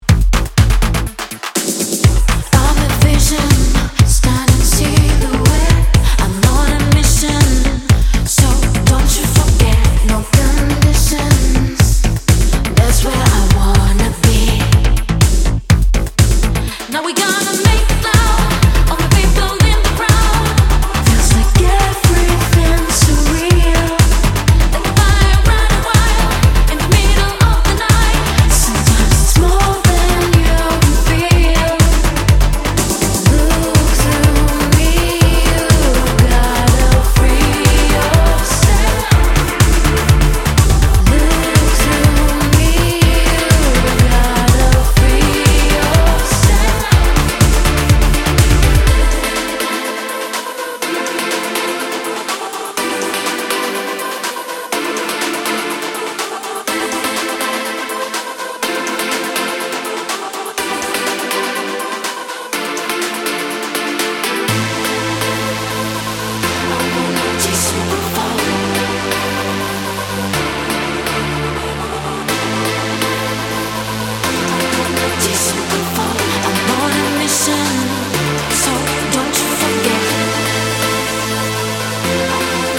sanguine vocals